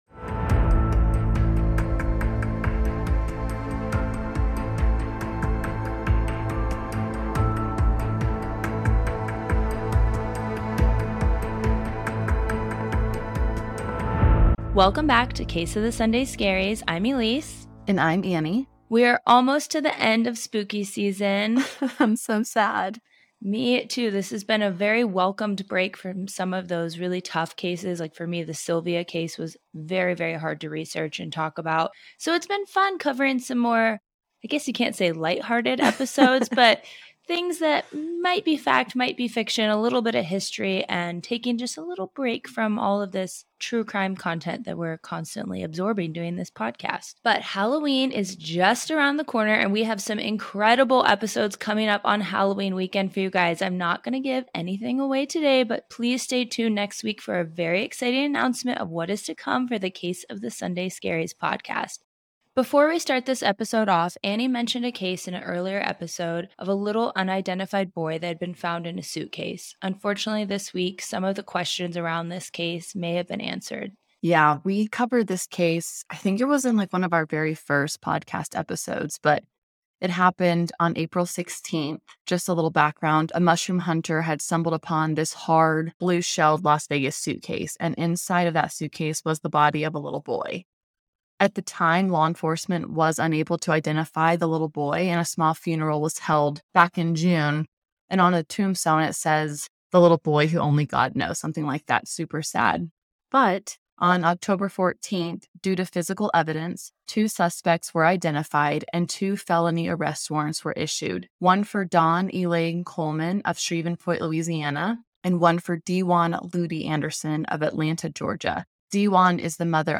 Today we uncover the Pharoah's Curse, is it true or just a wild coincidence and how did corpse medicine become an excuse for human cannibalism? **We apologize for the audio issue at the end of this podcast, we have worked to remedy it and appreciate your patience!